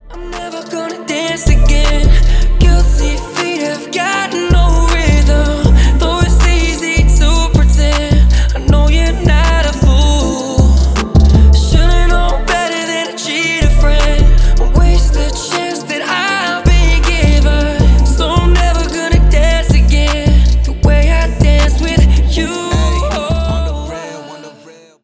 • Качество: 320, Stereo
красивый мужской голос
Cover